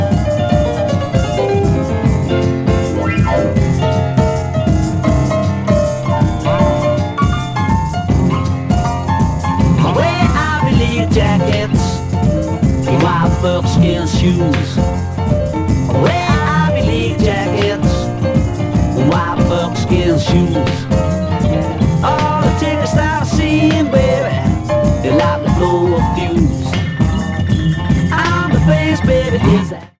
1964 UK Acetate